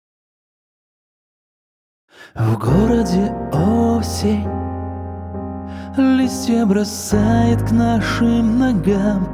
Меня интересует именно обработки которые лежат уже после чистки вокала, чем придается такая глубина, объем, ревер, дилэй, (все вместе) Выкладывать собссно практически нечего.
Маленький отрывок того чего я смог добиться над своим голосом.